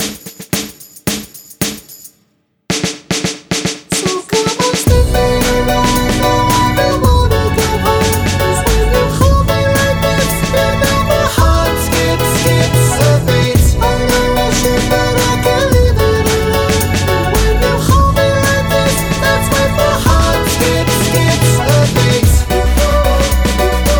no rap or Backing Vocals Pop (2010s) 3:24 Buy £1.50